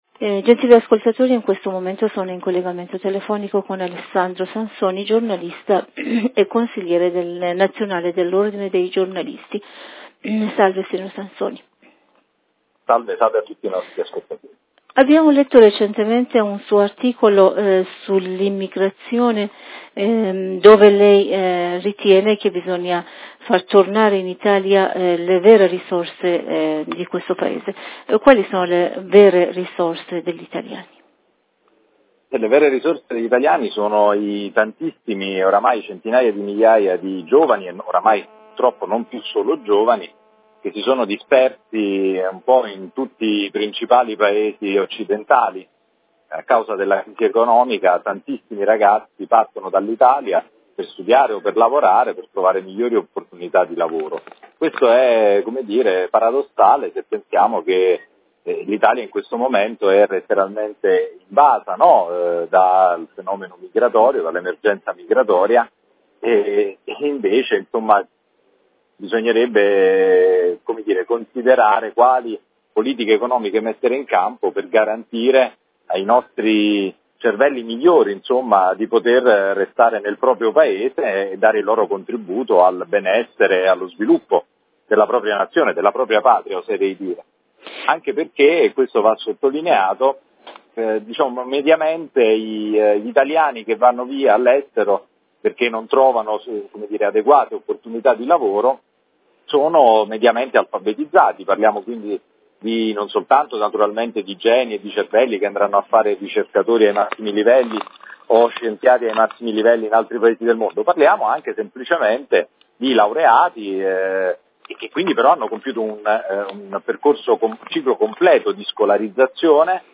TEHERAN (Parstoday Italian) – Immigrazione, la fuga dei cervelli e le elezioni politiche 2018 e' il tema dell'intervista realizzata dalla Redazione IRIB.